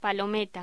Locución: Palometa